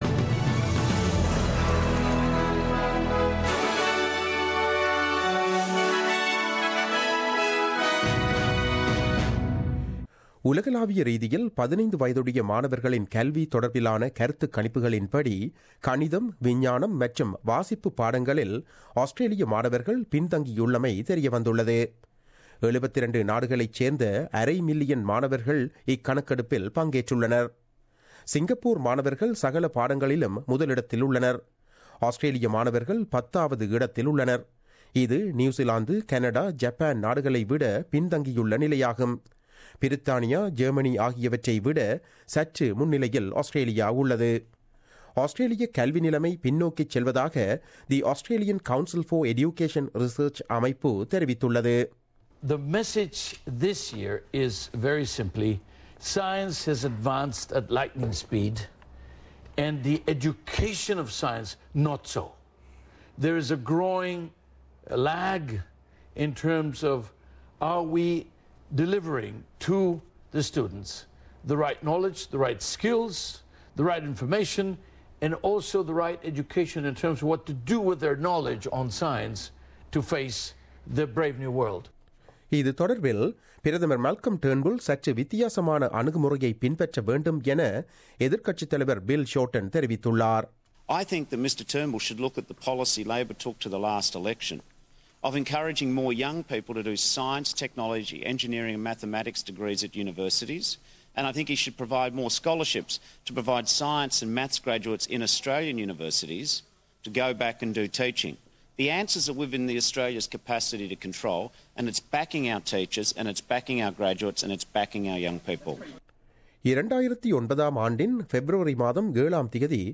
The news bulletin aired on 07 December 2016 at 8pm.